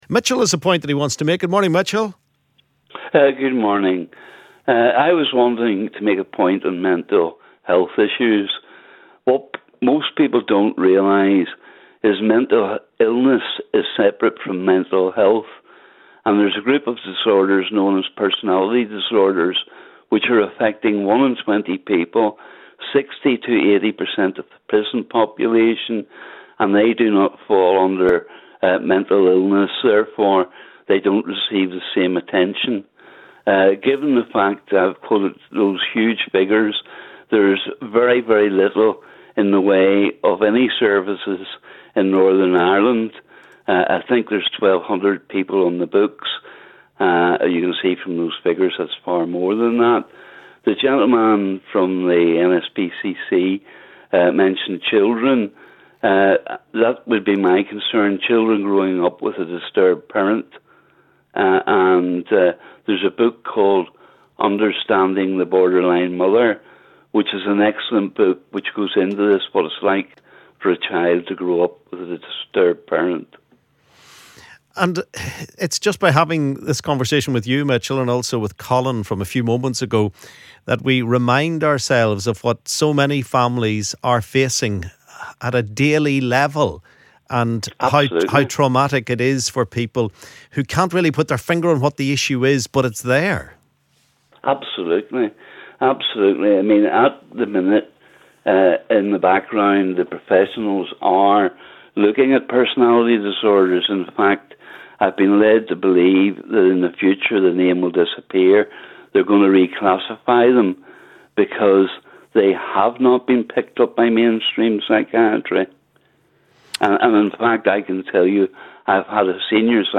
LISTEN ¦ Caller raises lack of help for those with personality disorders in NI